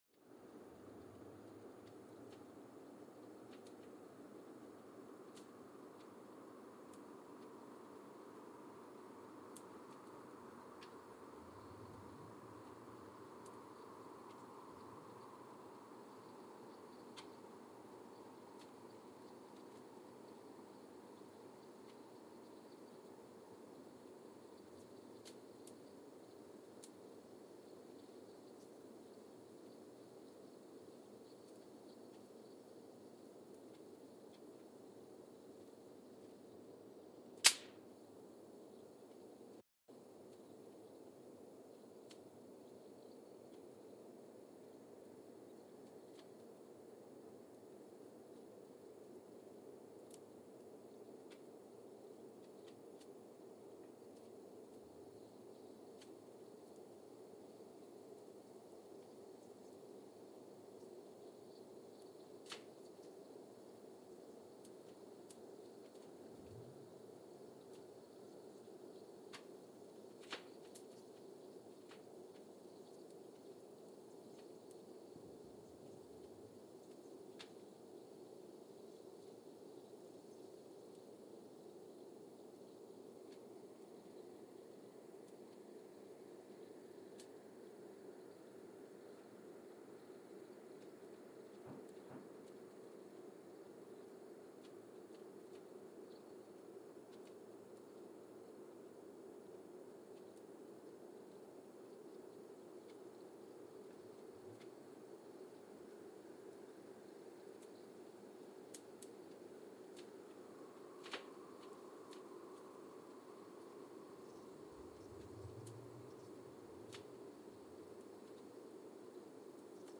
Mbarara, Uganda (seismic) archived on December 27, 2022
Sensor : Geotech KS54000 triaxial broadband borehole seismometer
Recorder : Quanterra Q330 @ 100 Hz
Speedup : ×1,800 (transposed up about 11 octaves)
Loop duration (audio) : 05:36 (stereo)
SoX post-processing : highpass -2 90 highpass -2 90